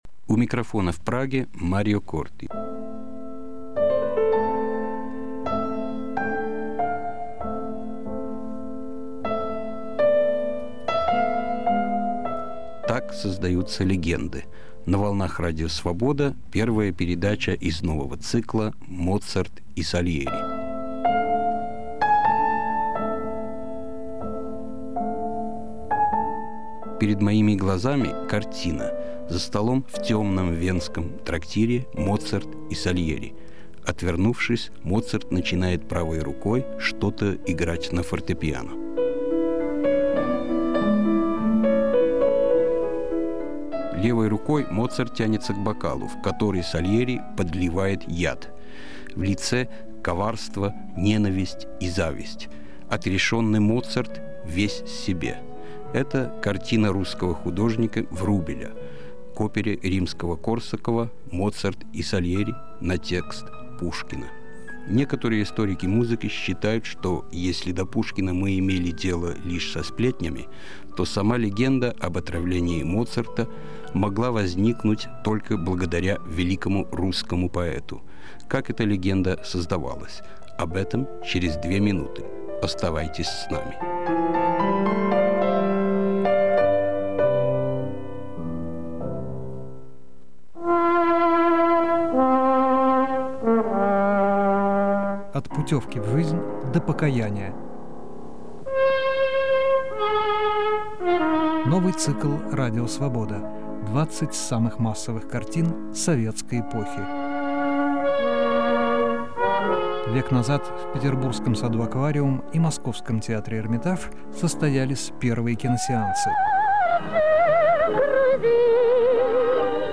Моцарт и Сальери. Историко-музыкальный цикл из девяти передач, 1997 год.